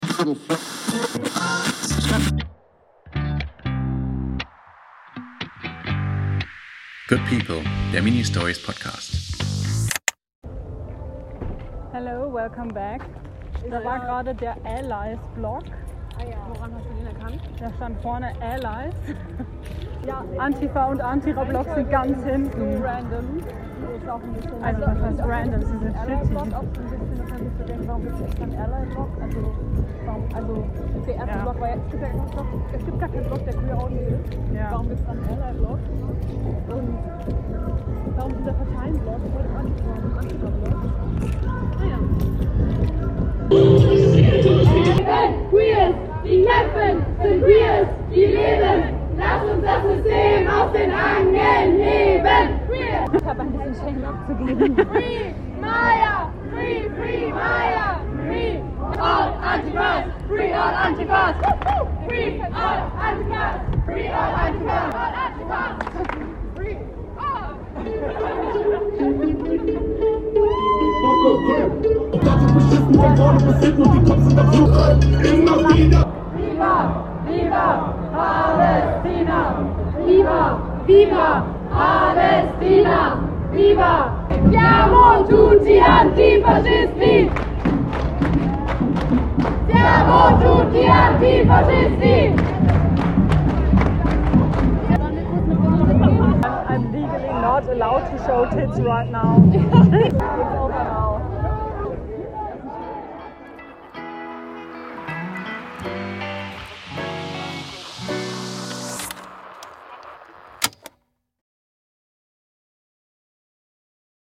Soundcollagierend gehen wir auf den ersten CSD in Hildesheim, wir lesen Statistiken zu transfeindlicher Gewalt, wie immer weinend und dann chanten wir ein Gedicht von ALOK, um uns zu reminden, wie magical und powerful wir trans* people sind.